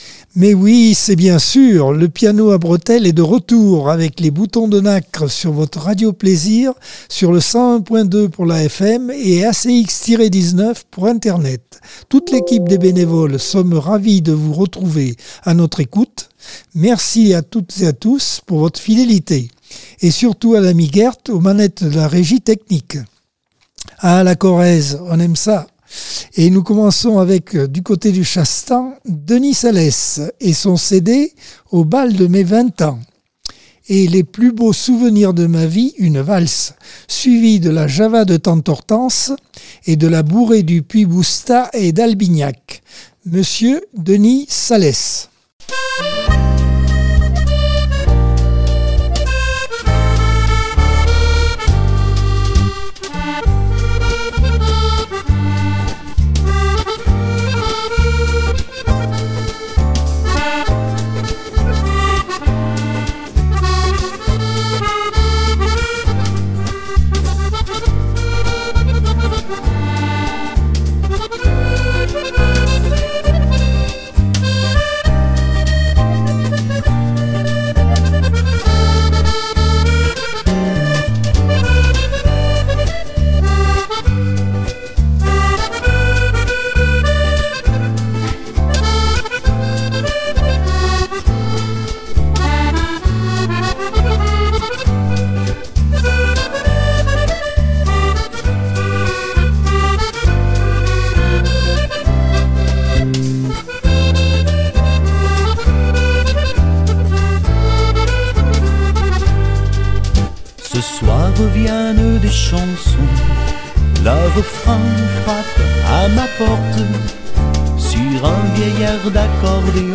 Accordeon 2024 sem 20 bloc 1 - Radio ACX